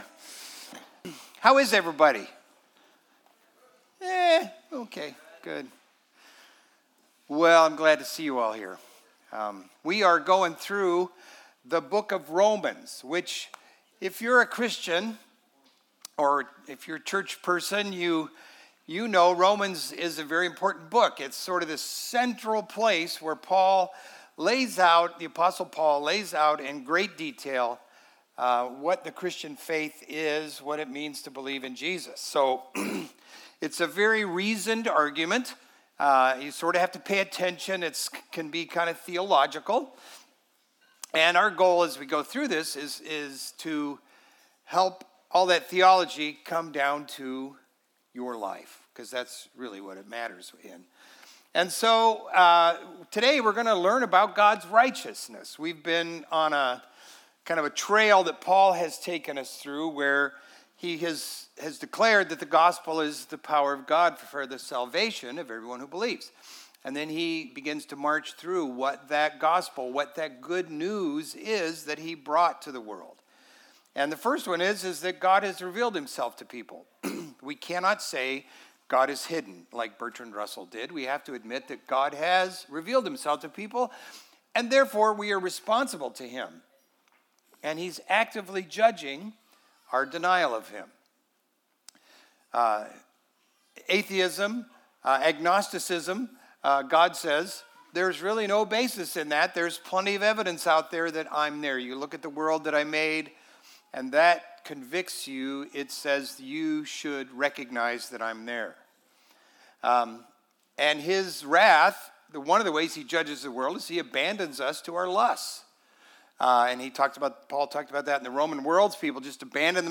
Download Audio Home Resources Sermons God's Righteousness Jun 29 God's Righteousness After shutting down all hope of us pleasing God, Paul reveals the brilliant solution God has to bring us into relationship with Him.